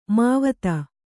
♪ māvata